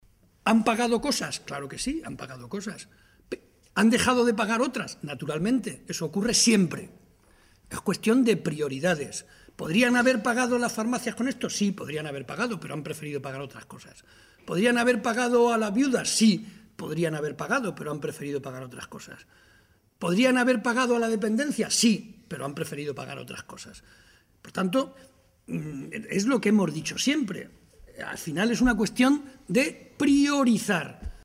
Momento de la rueda de prensa.